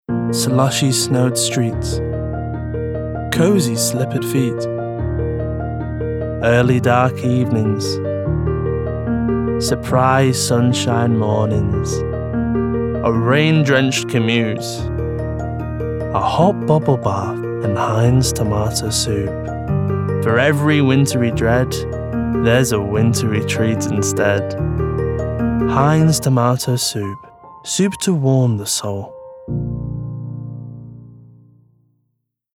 Voice Reel
Heinz - Warm, Relaxed